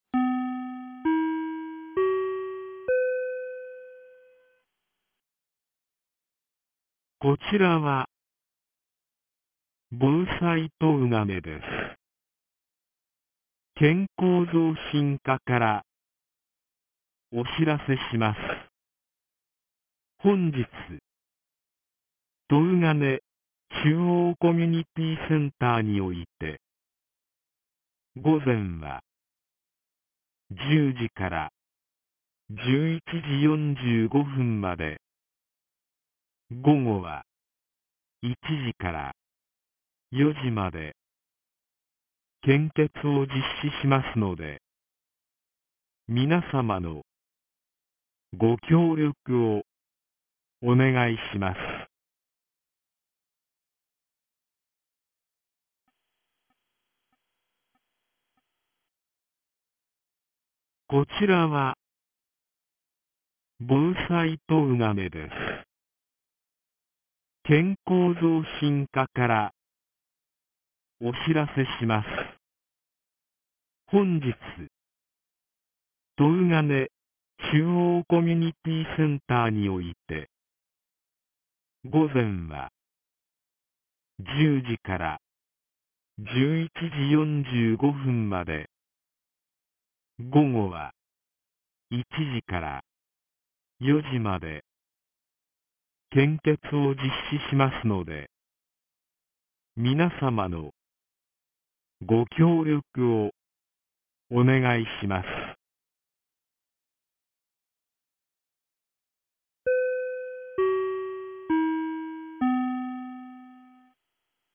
2025年05月08日 09時32分に、東金市より防災行政無線の放送を行いました。